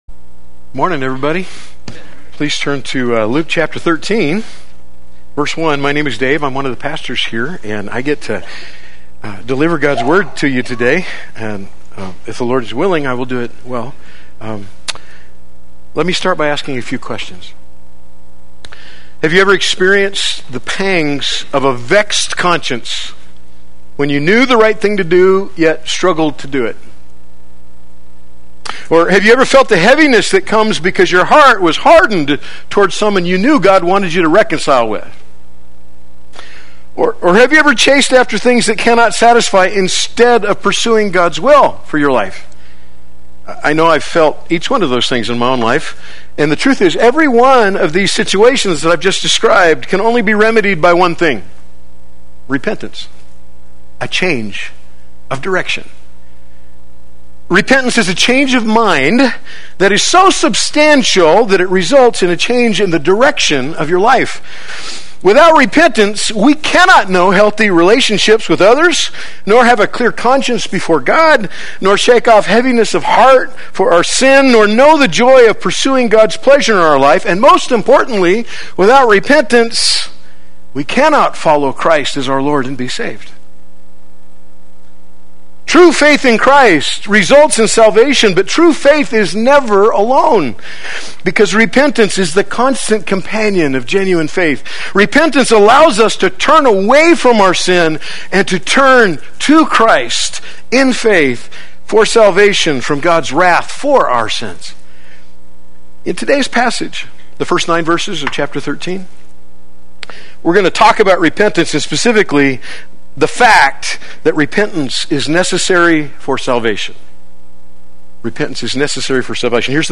Play Sermon Get HCF Teaching Automatically.
Time to Repent Sunday Worship